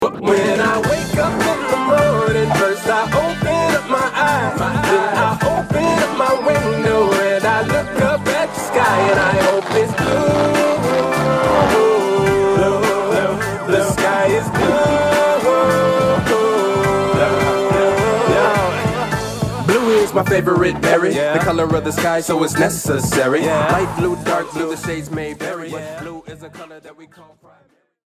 Pop